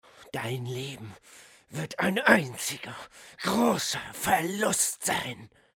The role of the villain Raul Menendez was filled by the winner of the Best Actor category in the German TV awards Deutschen Fernsehpreis 2012, Wotan Wilke Möhring.